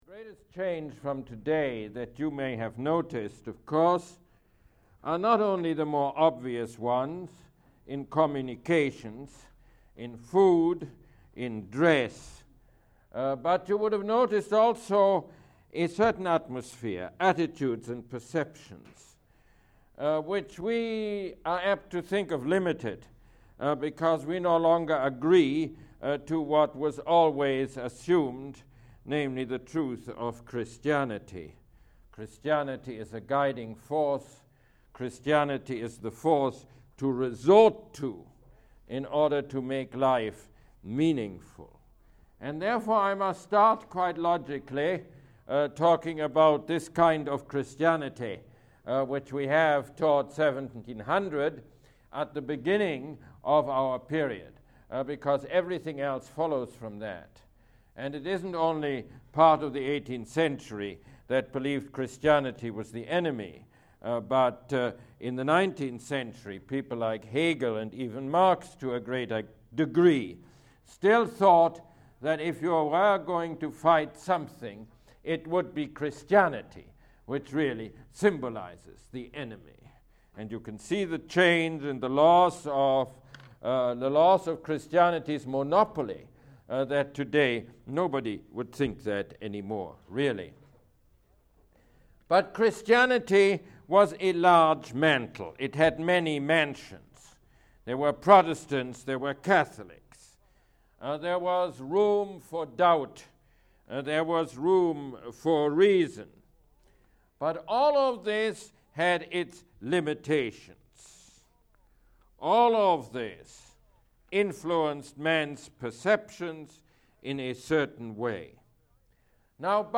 Mosse Lecture #2